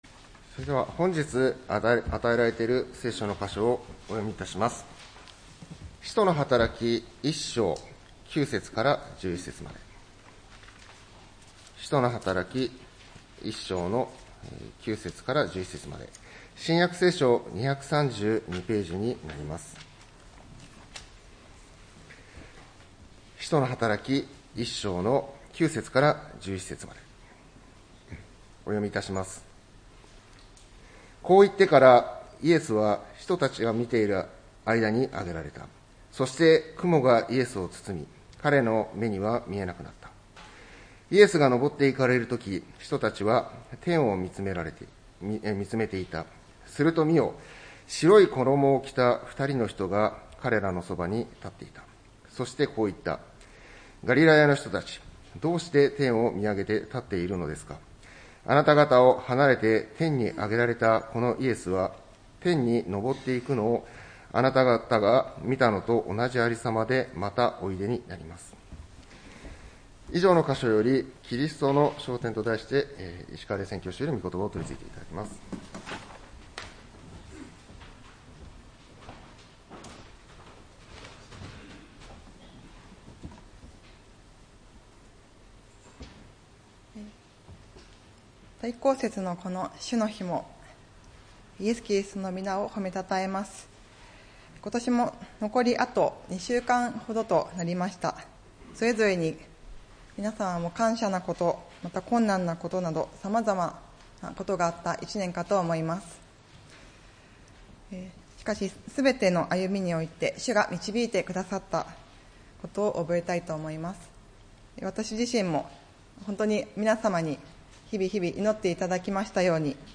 礼拝メッセージ「キリストの昇天」(12月15日）